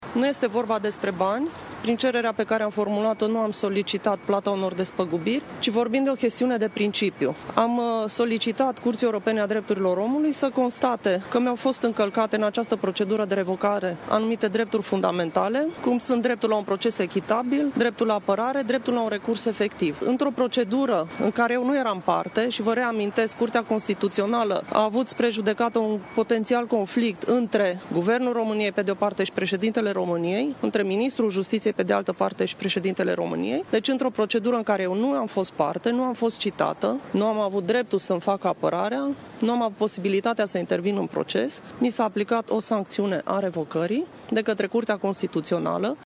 Fostă șefă a Direcției Anticorupție, Laura Codruța Kovesi le-a explicat jurnaliștilor, miercuri dimineață, de ce a atacat la Curtea Europeană a Drepturilor Omului decizia revocării din funcție.